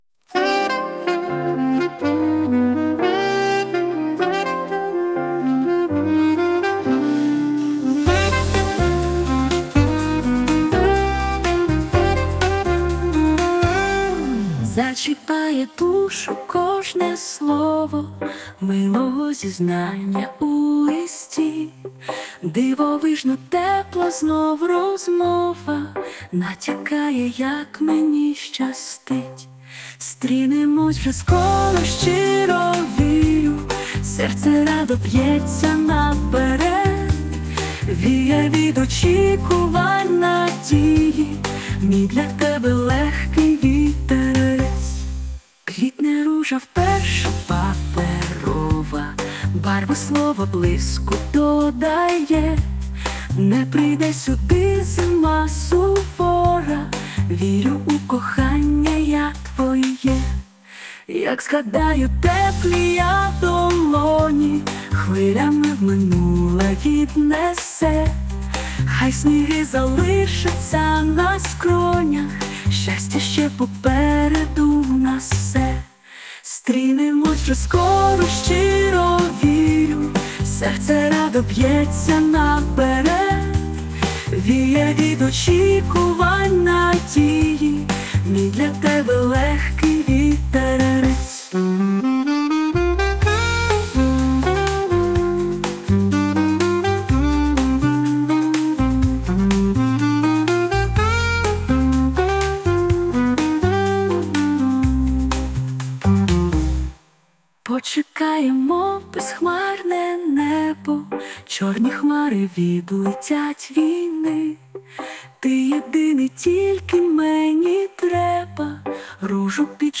Музичний супровід створено за допомогою SUNO AI
СТИЛЬОВІ ЖАНРИ: Ліричний